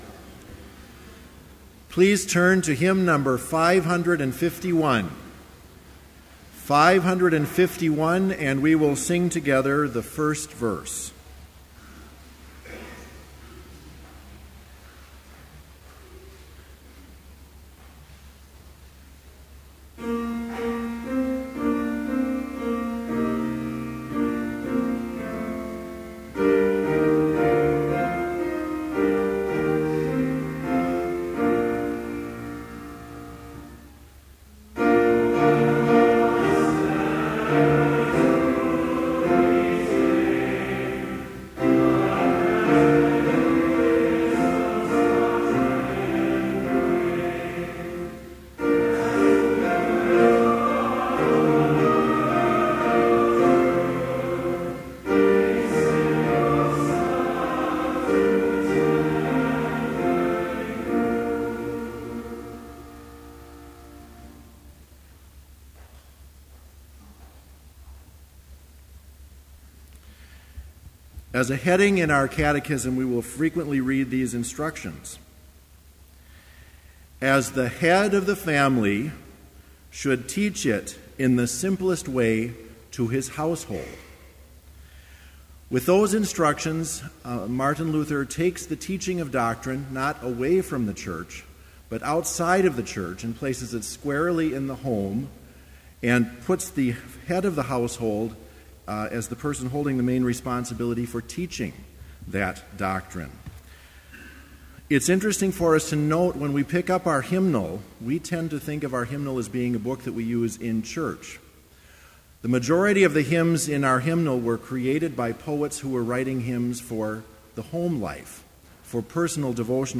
Complete service audio for Chapel - January 22, 2013